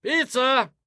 Курьер за дверью кричит про доставленную пиццу